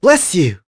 Lucias-vox-Happy4.wav